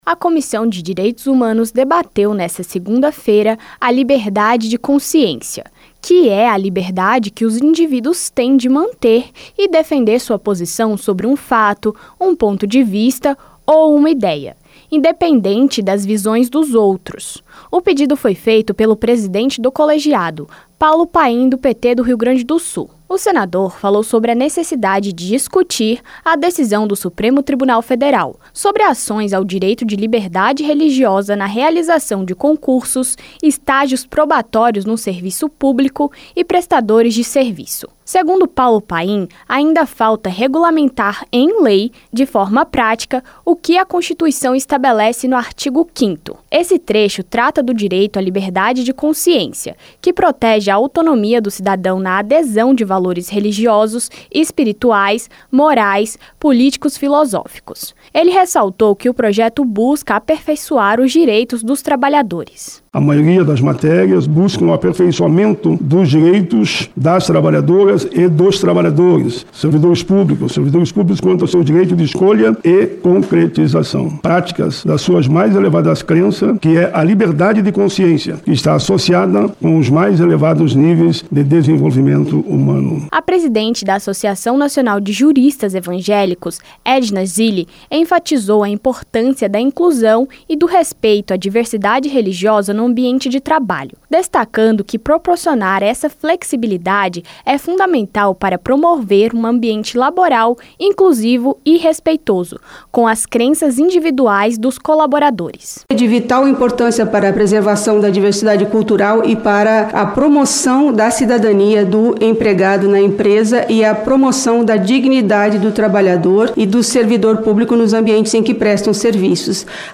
A Comissão de Direitos Humanos (CDH) debateu nesta segunda-feira (26), em audiência pública, a liberdade de consciência.
O presidente da comissão, senador Paulo Paim (PT-RS), falou sobre ações relativas ao direito de liberdade religiosa na realização de concursos e estágios probatórios no serviço público.